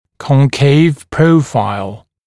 [kɔŋ’keɪv ‘prəufaɪl][кон’кейв ‘проуфайл]вогнутый профиль